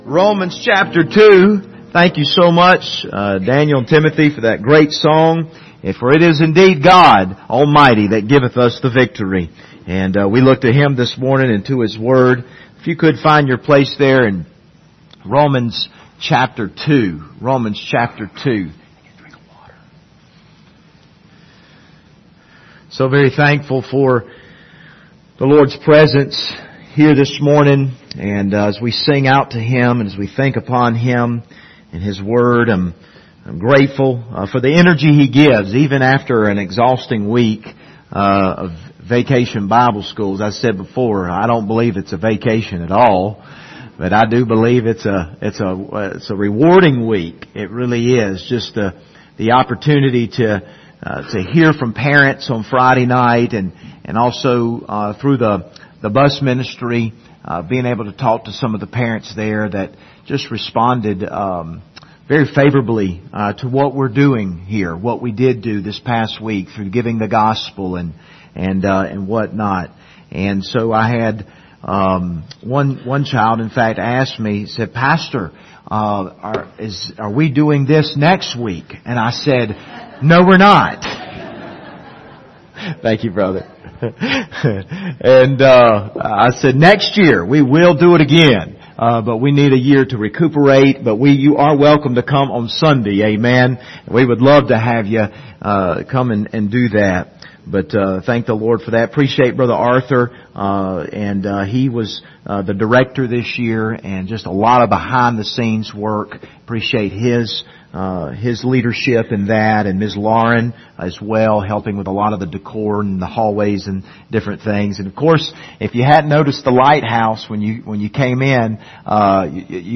Passage: Romans 2:1-6 Service Type: Sunday Morning